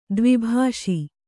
♪ dvi bhāṣi